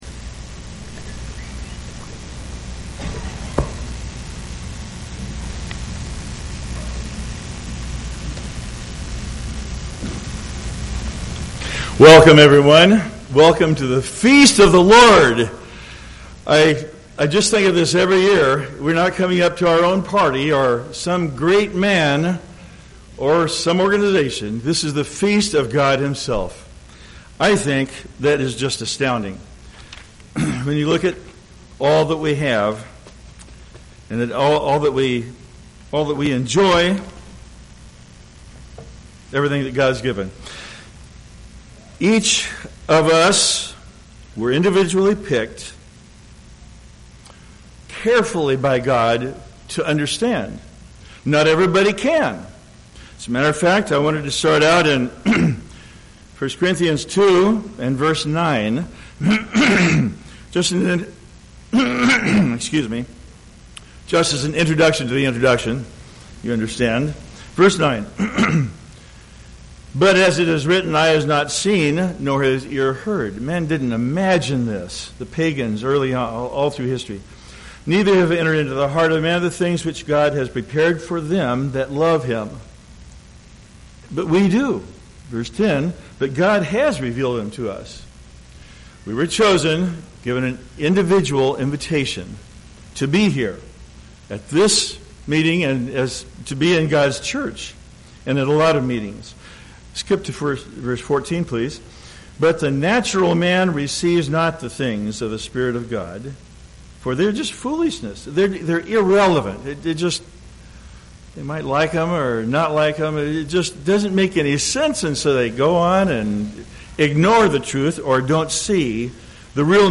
This sermon was given at the Oconomowoc, Wisconsin 2016 Feast site.